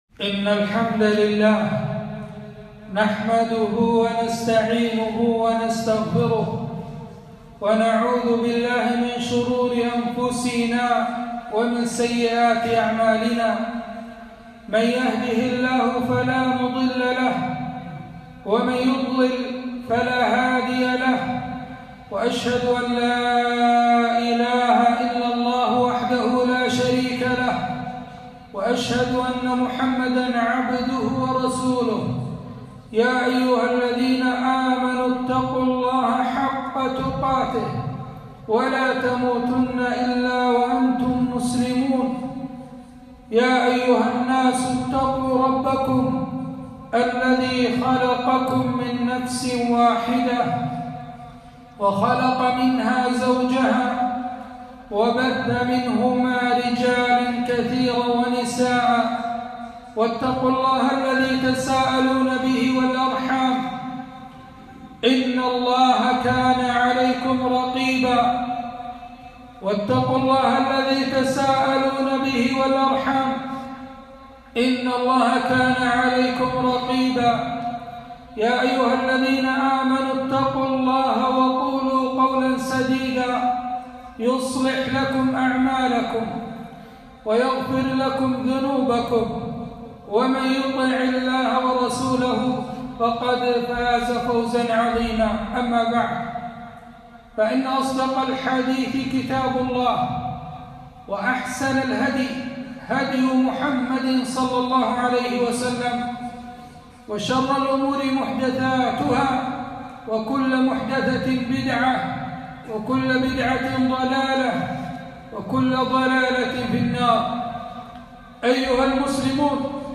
خطبة - قل هو الله أحد